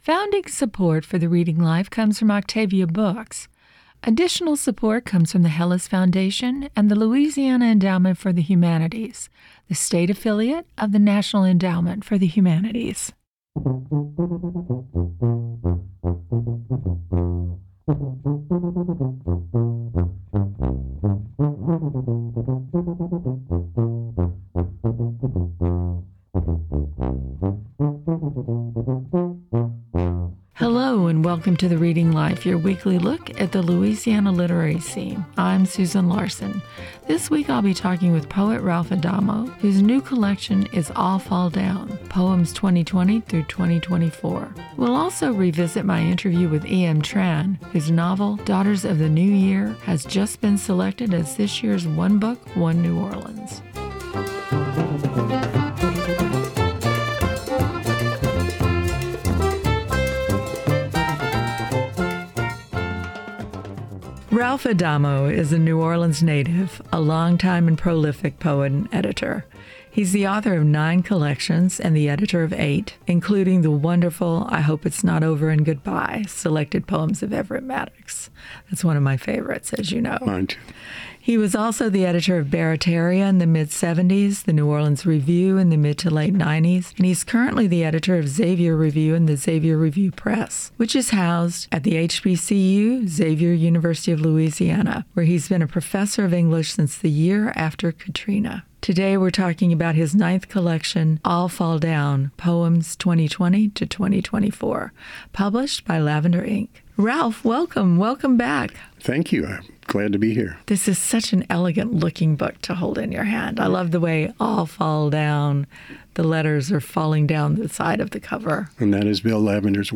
Hear celebrated and up-and-coming authors read excerpts from new books and discuss their work